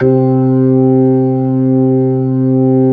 Index of /90_sSampleCDs/AKAI S-Series CD-ROM Sound Library VOL-8/SET#5 ORGAN
HAMMOND  10.wav